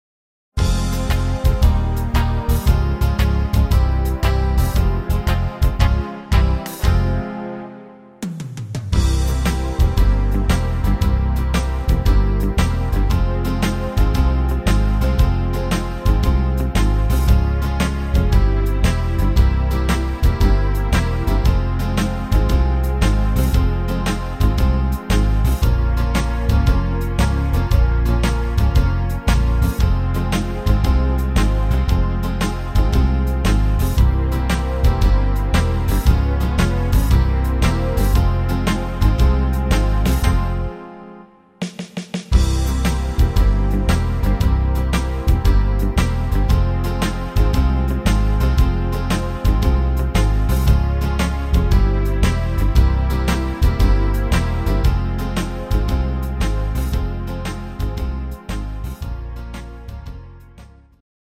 instrumental Saxophon